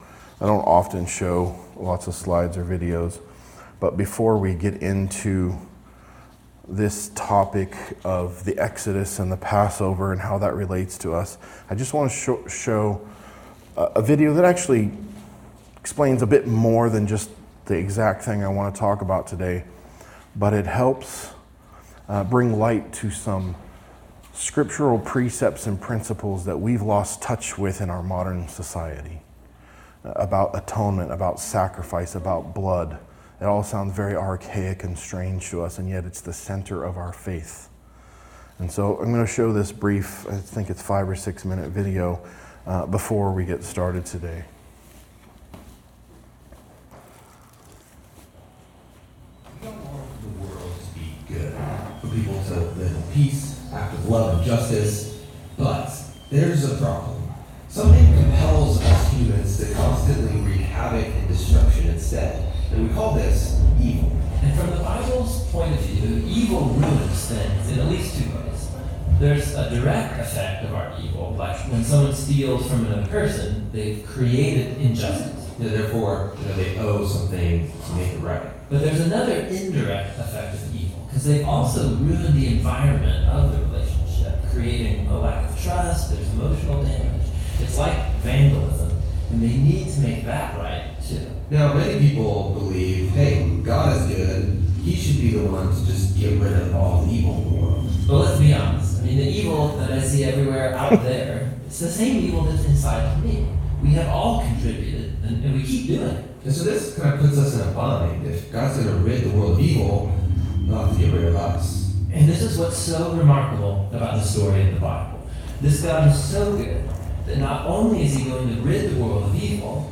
Topical message on the Exodus and Passover in preparation for Resurrection Sunday the following week.